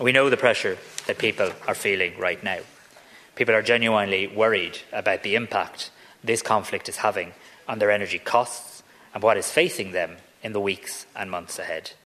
Tánaiste Simon Harris says these cuts will be effective for all: